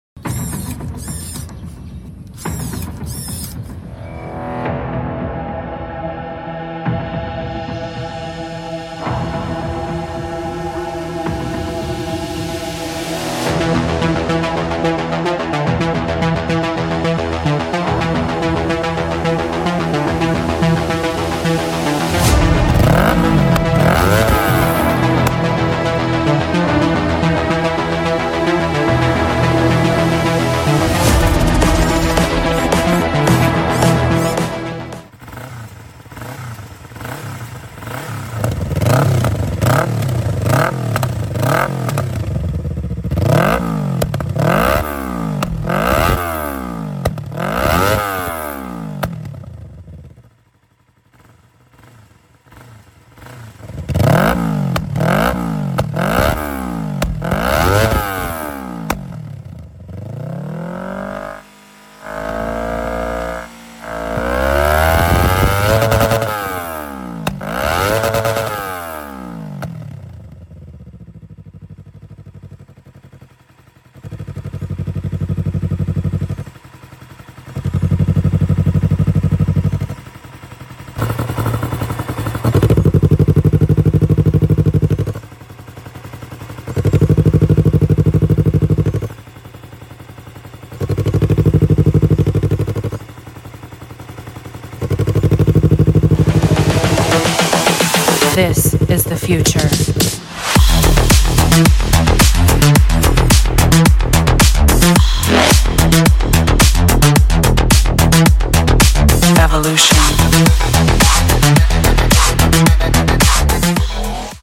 Tridente Cerberus Black Yamaha R25 Sound Effects Free Download